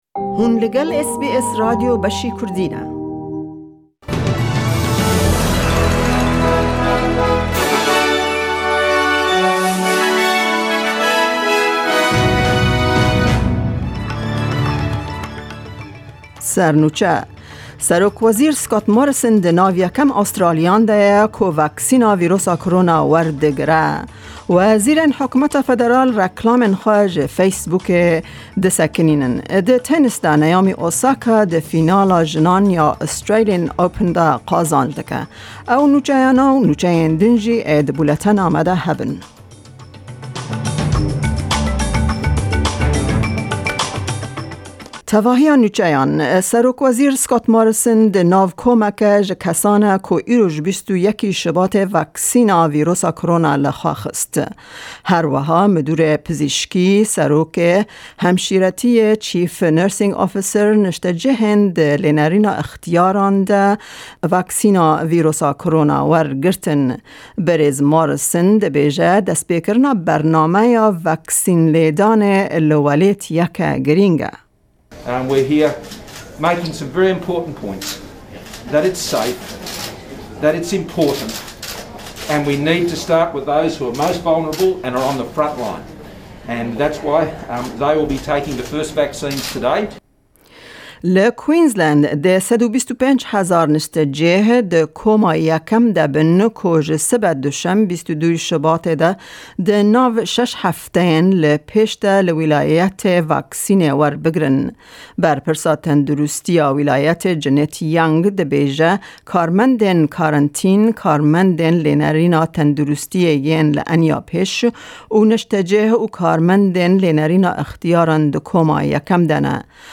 SBS Kurdish news for weekend of 20-21 February 2021.